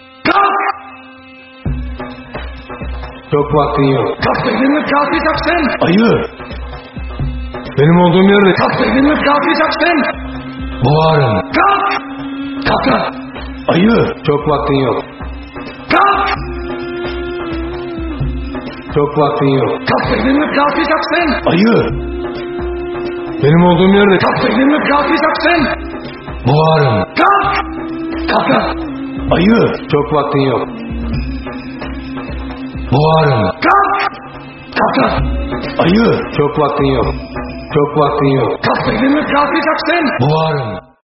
Kategori: Zil Sesleri
Ünlü diziden Memati'nin ikonik uyarısıyla güne başlamaya ne dersiniz?